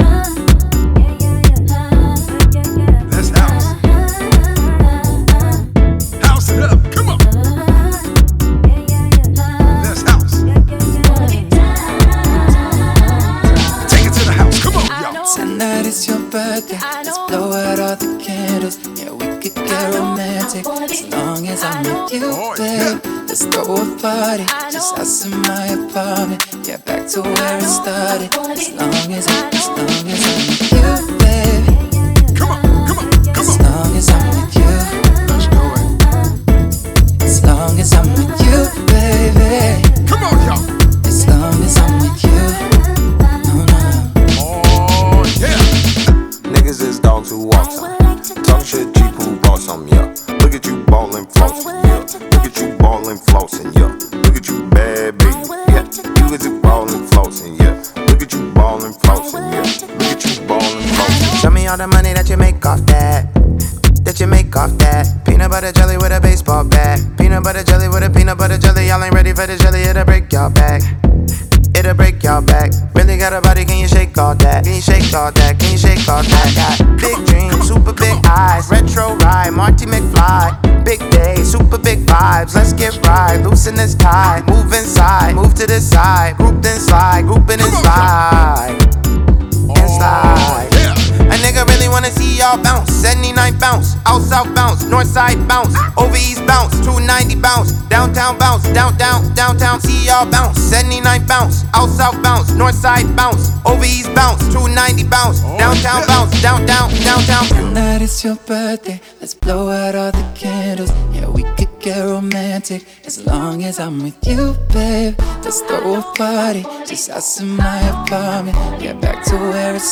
BPM63-125
Audio QualityPerfect (High Quality)
CommentsBPM 62.5 - 125